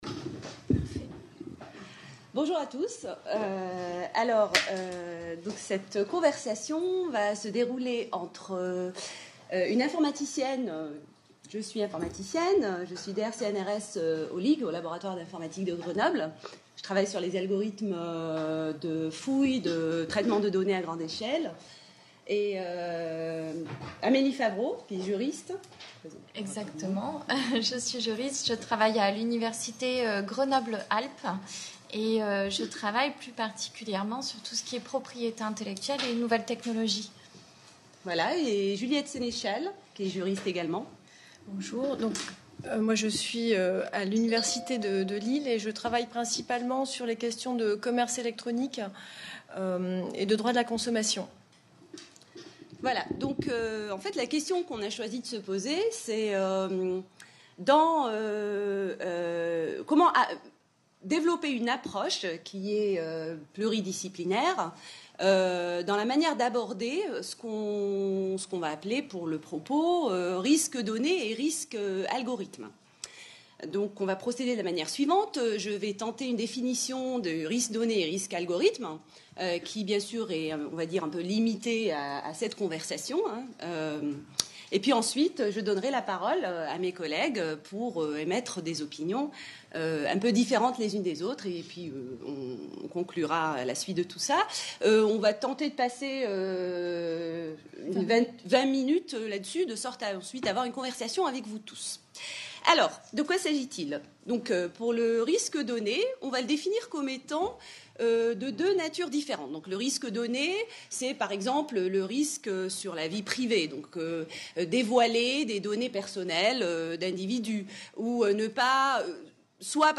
Conversation.